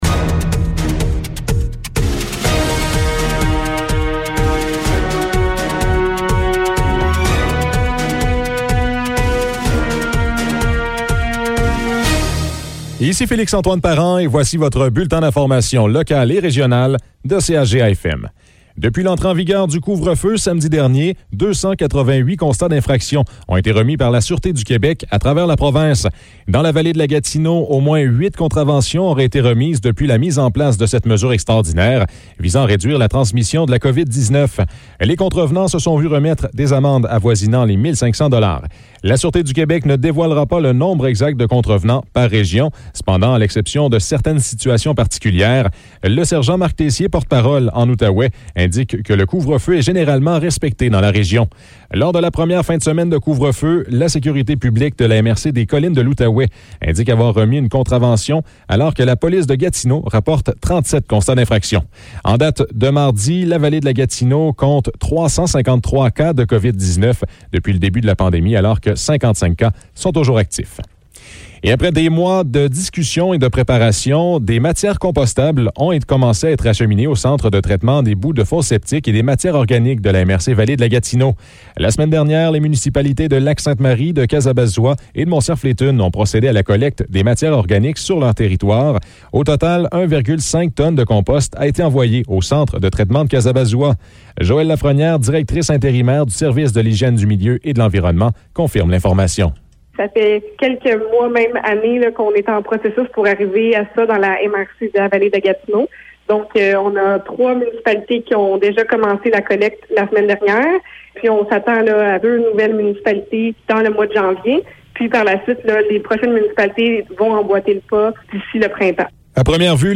Nouvelles locales - 12 janvier 2021 - 12 h